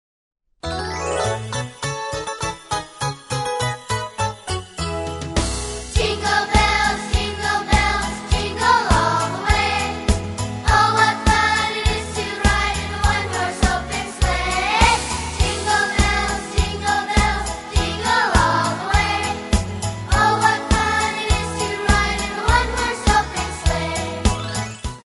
Listen to a sample of this vocal song track.